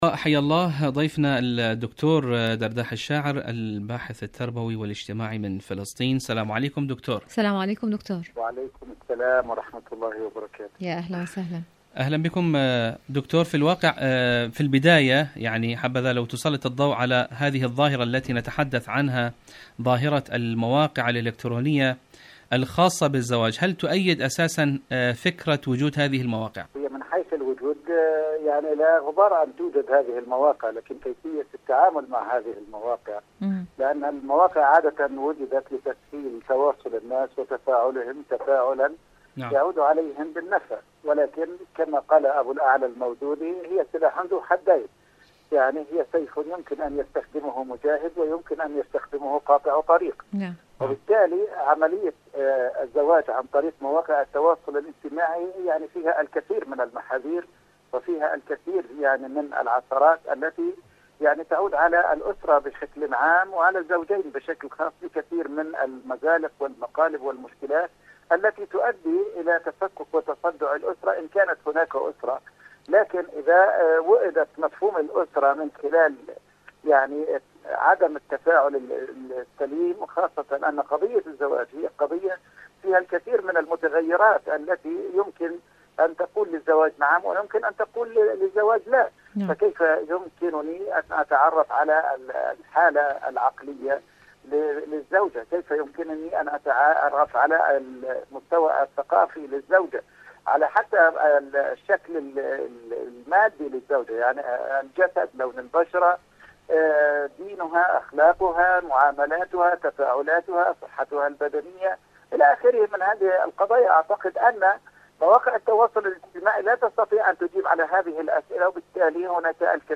الزواج في العالم الافتراضي.. مقابلة
إذاعة طهران-دنيا الشباب: مقابلة إذاعية